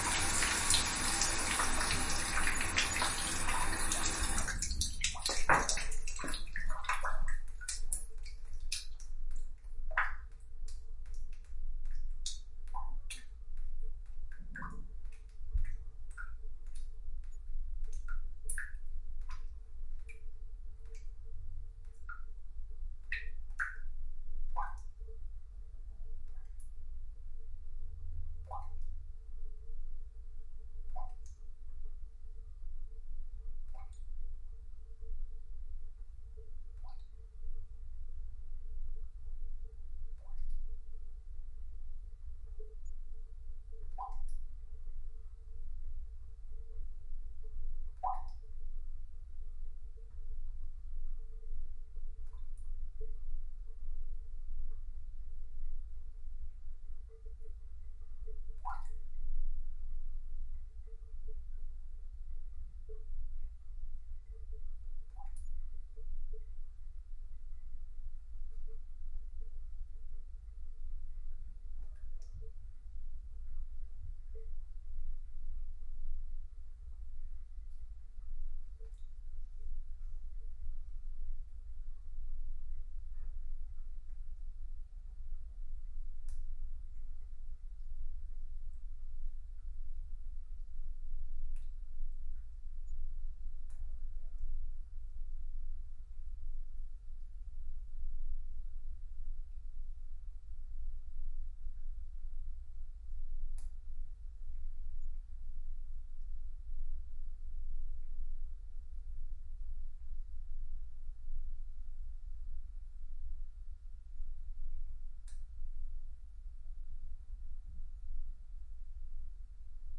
花洒排水管 3
描述：现场记录的水沿着我的水槽排水管。记录在Edirol R09HR 128kbps 16位立体声。
Tag: 现场录音 排水 供水 淋浴